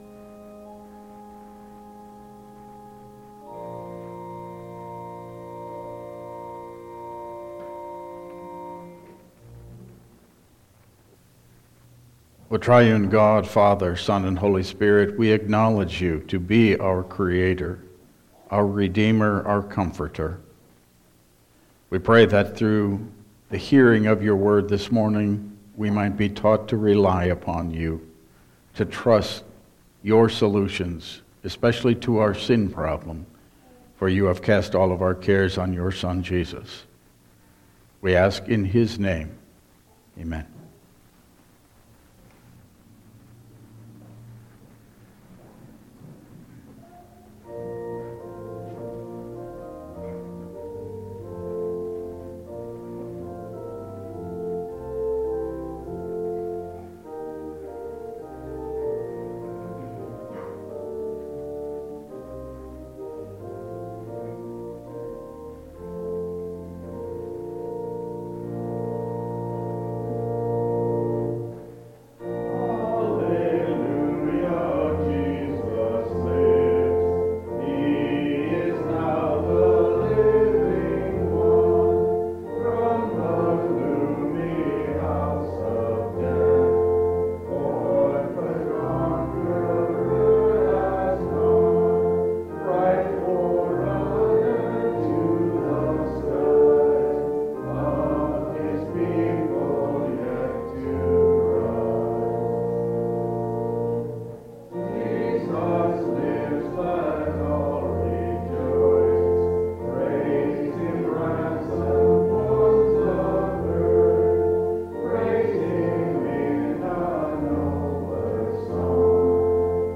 Passage: Acts 9:1-20 Service Type: Regular Service